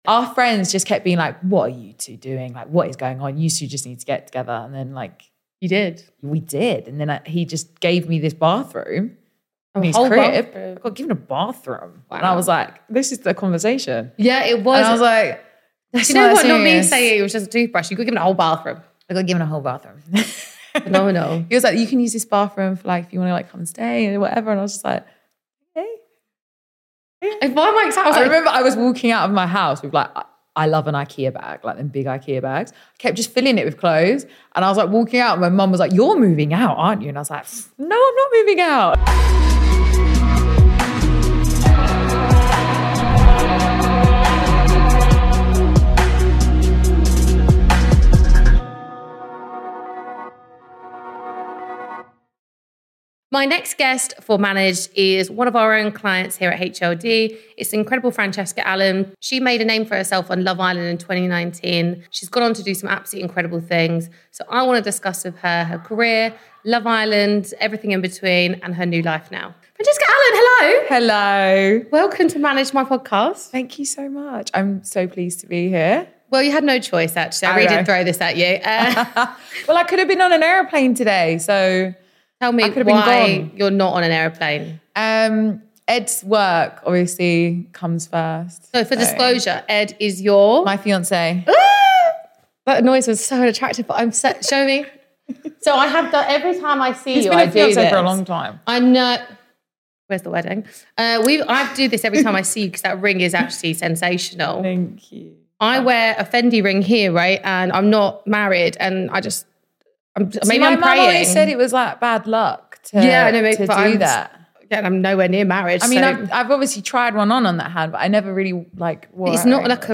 Managed is a podcast/ digital show interviewing industry leaders across media, business, sports and more who found success at a young age.